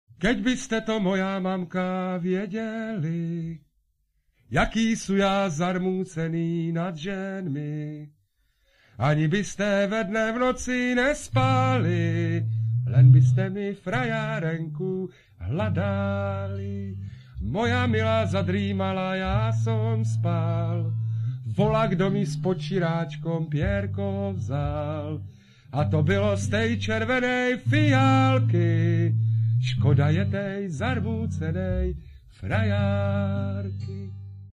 Lidové písně zpívané | Jarek Nohavica